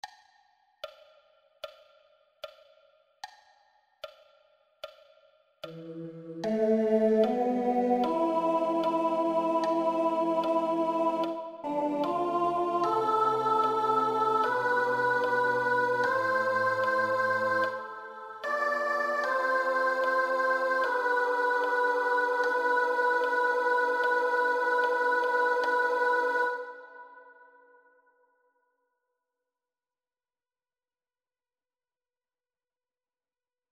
Key written in: A Minor